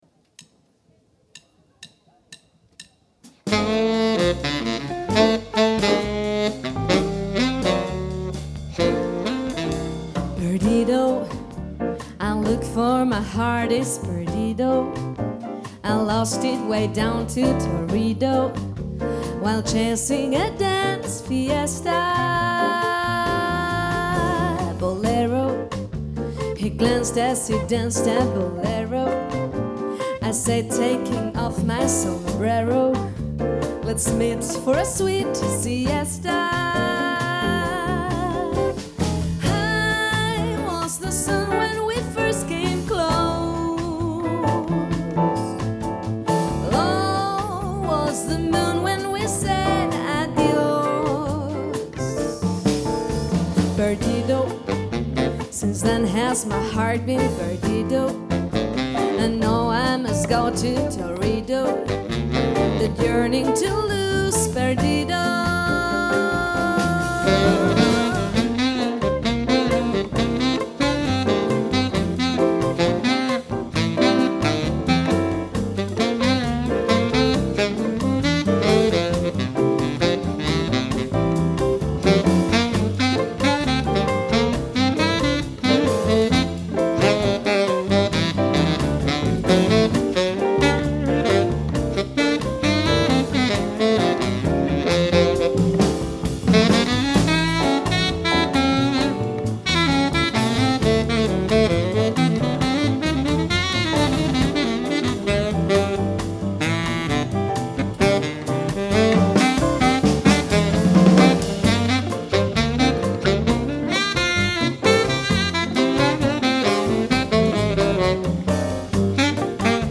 neulich hatte ich das vergnügen, eine band zu filmen und parallel audio aufzuzeichnen. audio ging dikrekt aus der console in meinem kram, aber nicht ganz so direkt wie ich mir das gedacht hätte. hier einmal mein mixversuch, danke für hilfreiche…